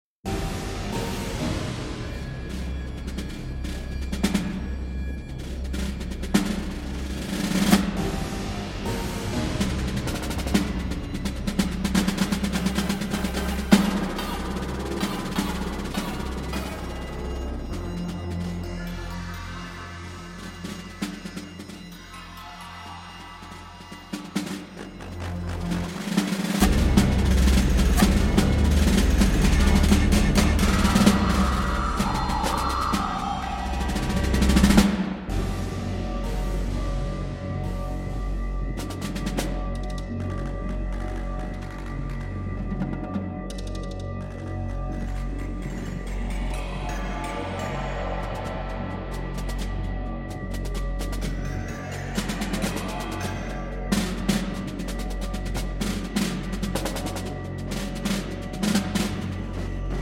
Snare Drum